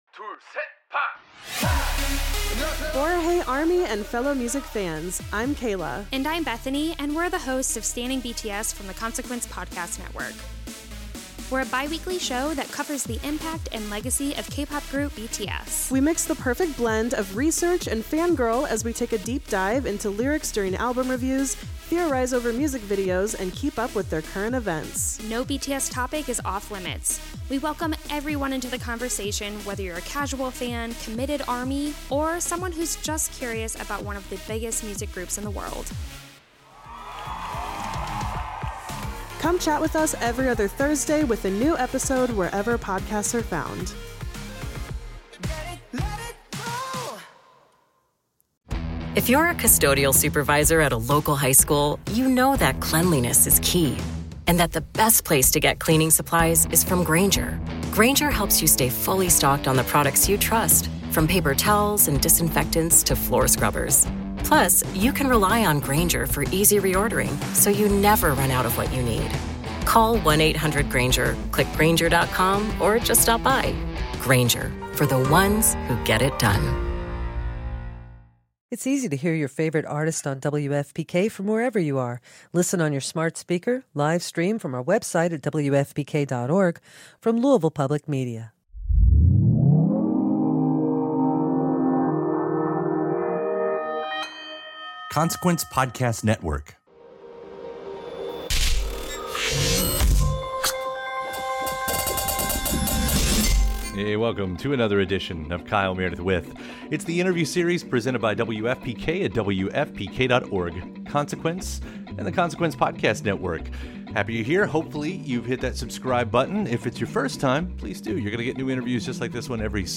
an interview series